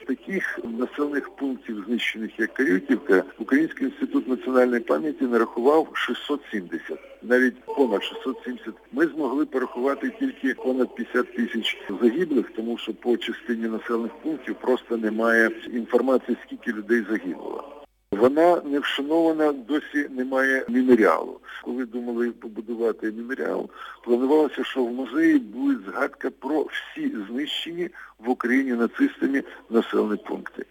Розповідає історик